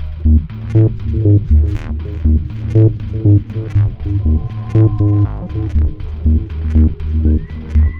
Scary Ambience Eb 120.wav